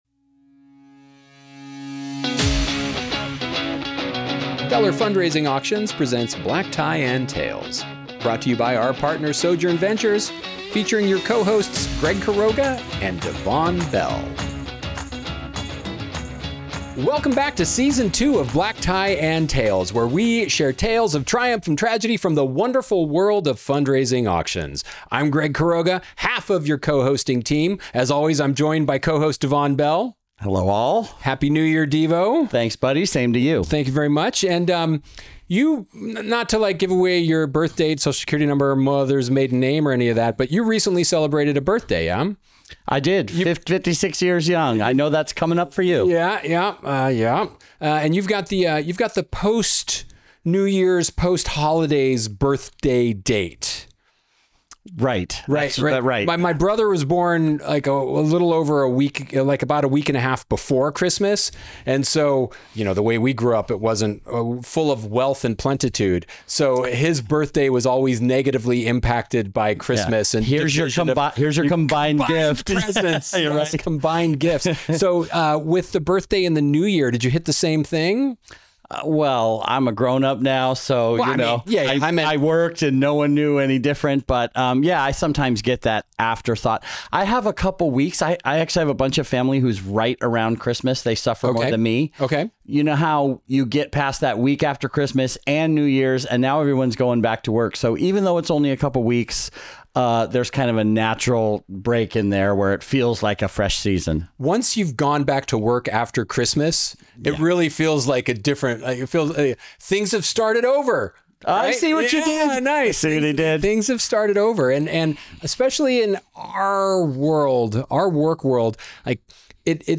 Take a peek behind the curtain of the wonderful world of fundraising auctions with two auctioneers who have seen it all.